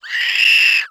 CREATURE_Squeel_01_mono.wav